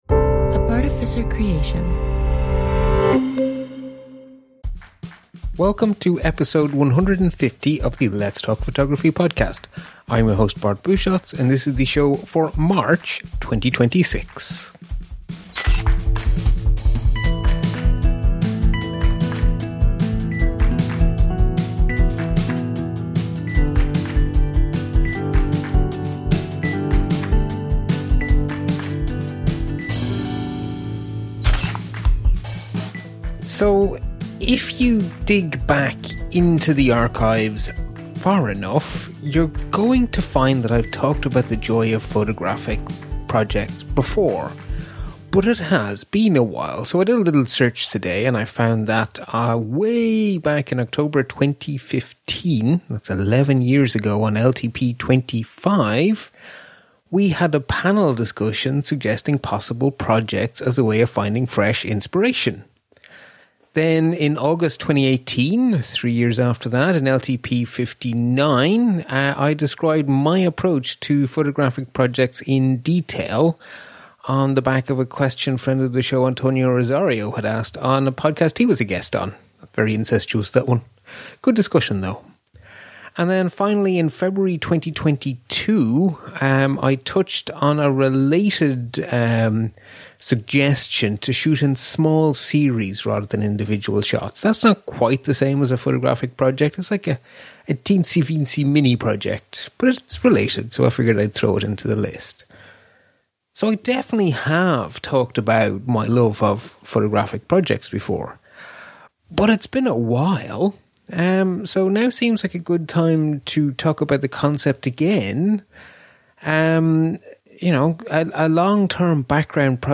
In this solo show